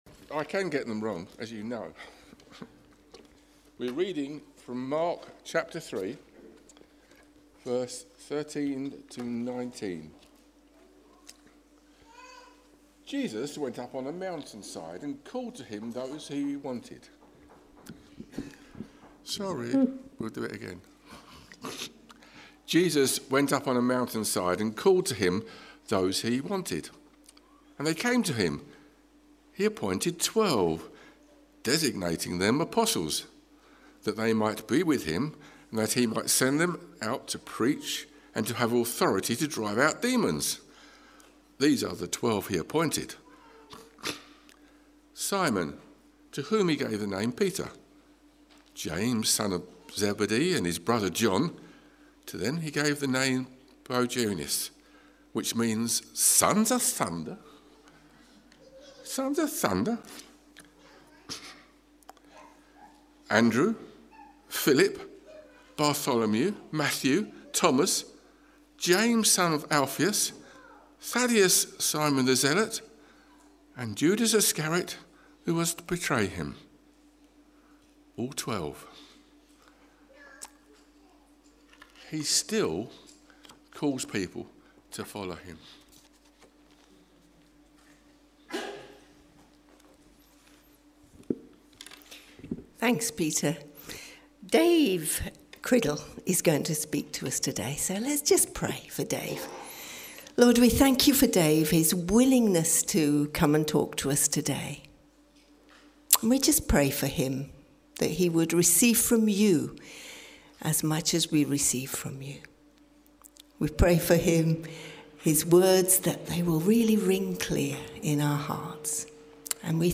Talk
A talk from the series "The Sermon on the Mount."